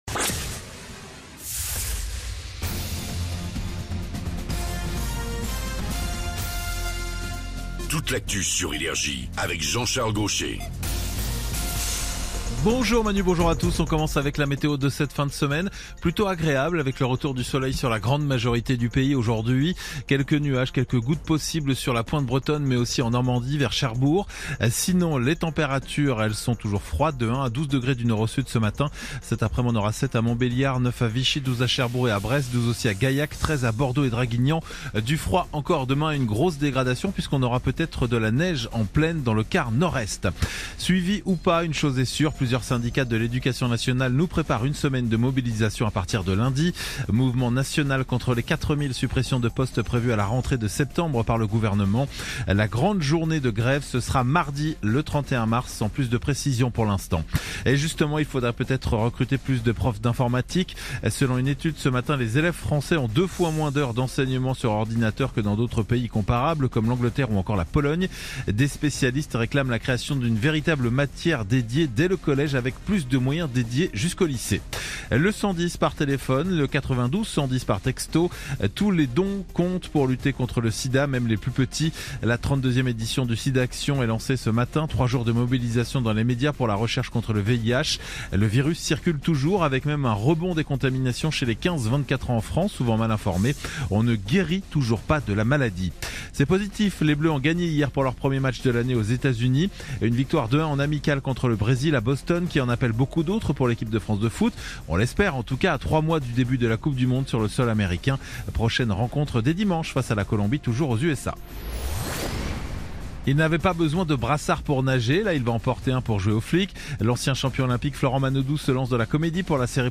Réécoutez vos INFOS, METEO et TRAFIC de NRJ du vendredi 27 mars 2026 à 08h00